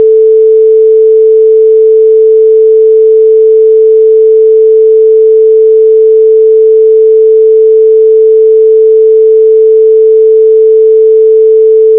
Si la fréquence n’est pas variable, le phénomène de grande baisse de la hauteur, ne se produit plus, comme on peut l’entendre avec les deux sons suivants, pour lesquels la hauteur est constante : la fréquence de départ de 440 Hz pour le premier, et la fréquence finale de 5720 Hz pour le second. La fréquence d’échantillonnage est toujours de 11025 Hz (toujours sur 8 bits).